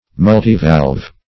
Multivalve \Mul"ti*valve\, Multivalvular \Mul`ti*val"vu*lar\, a.